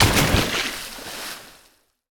water_splash_object_body_03.wav